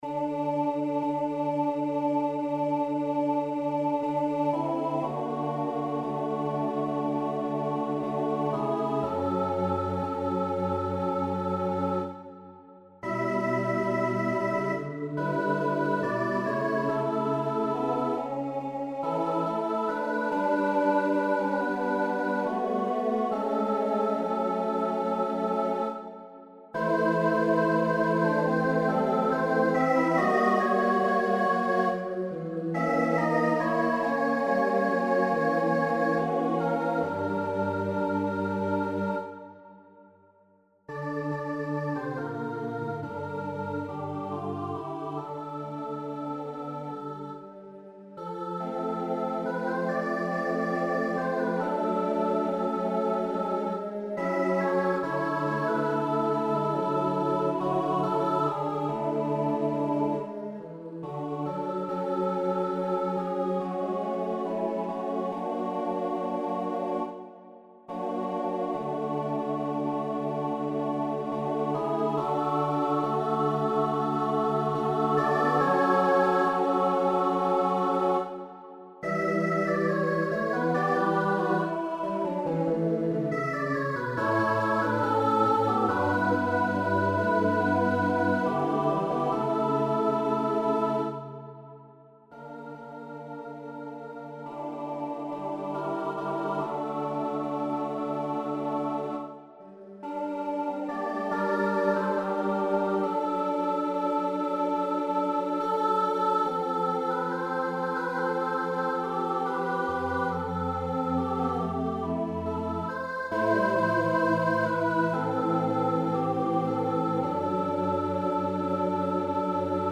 Joyful, slightly dreamy setting
Forces: SSAATTB (substantial divisi)
Midi demo (no one likes midi, but it’s better than nothing):